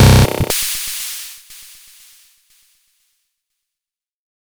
Ew Crash Fx.wav